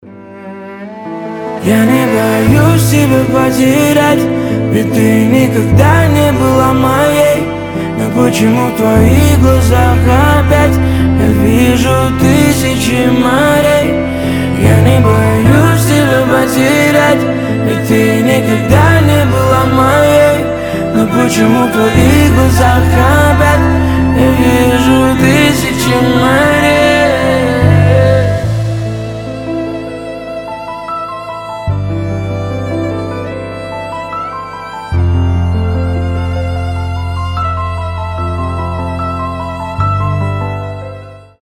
• Качество: 320, Stereo
мужской голос
лирика
грустные
дуэт
скрипка
пианино
виолончель